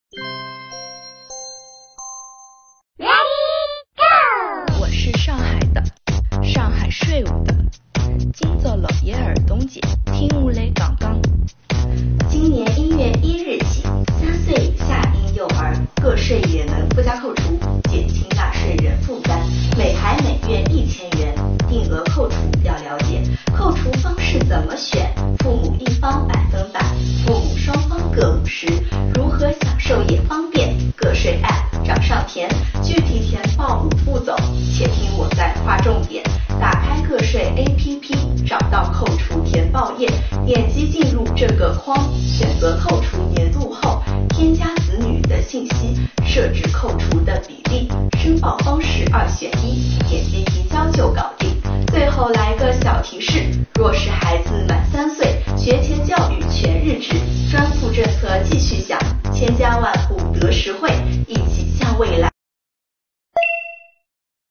来听听税务版上分，看税务小姐姐是如何介绍“3岁以下婴幼儿照护”纳入个税专项附加扣除的吧~